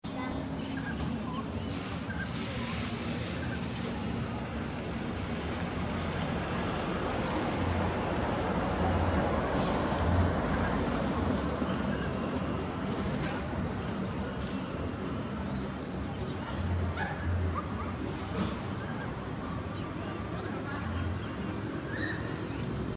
Chiricote (Aramides cajaneus)
El audio presenta ruidos propios de zona urbana.
Nombre en inglés: Grey-cowled Wood Rail
Localidad o área protegida: Concordia
Condición: Silvestre
Certeza: Vocalización Grabada